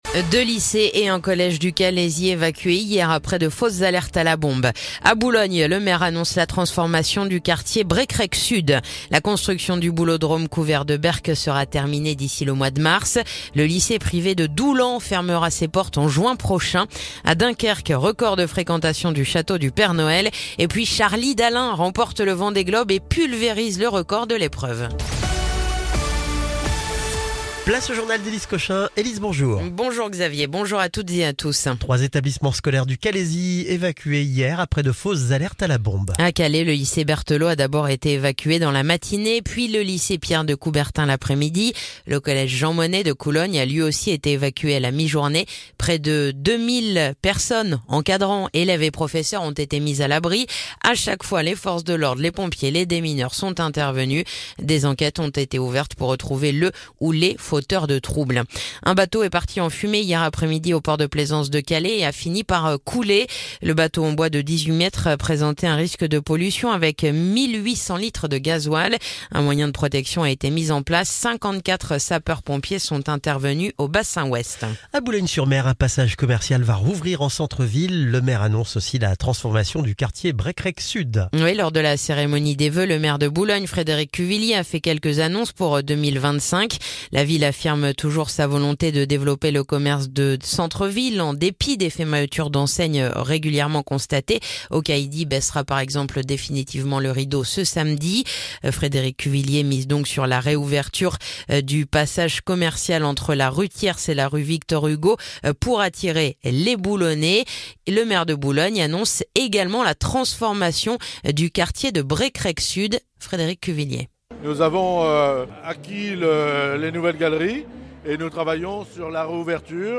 Le journal du mardi 14 janvier